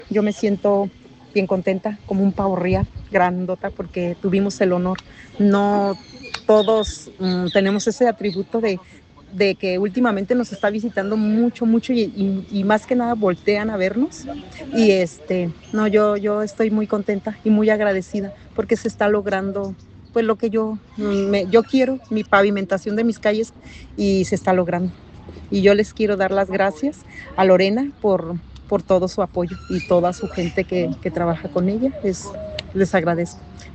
habitante de la colonia Plan Vivirá